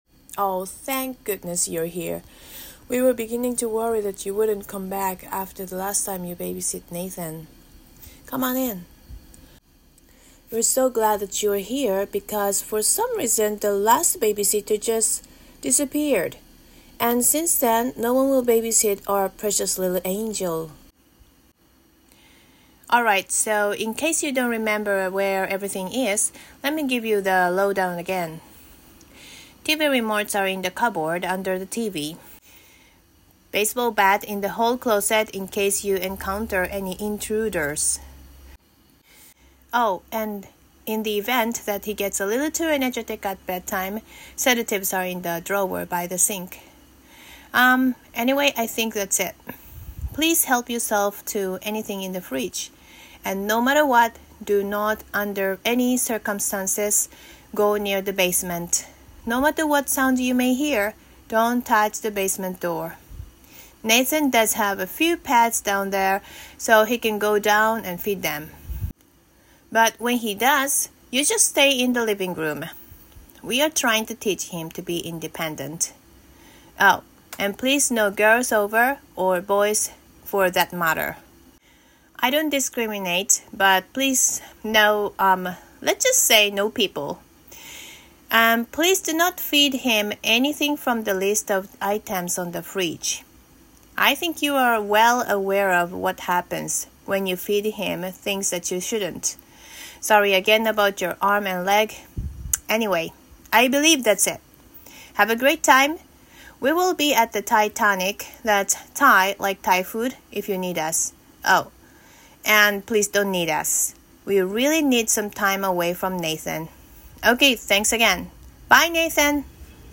Genre: Comedic